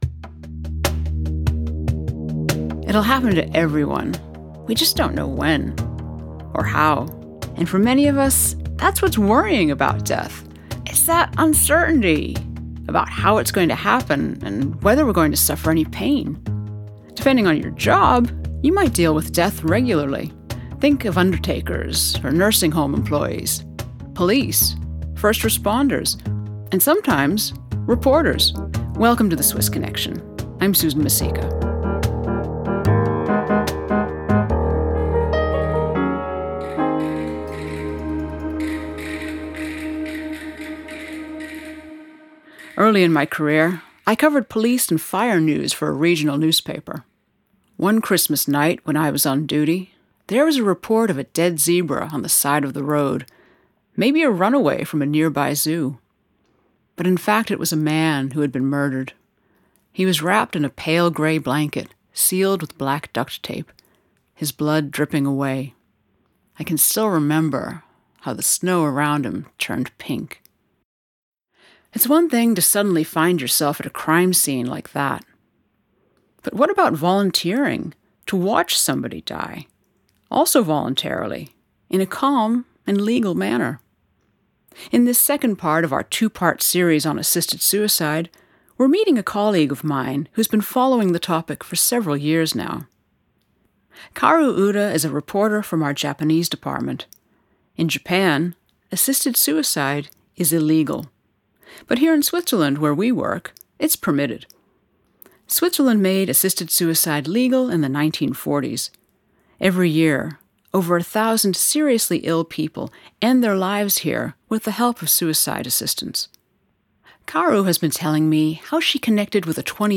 Journalists discuss how two Japanese people came to Switzerland for assisted suicide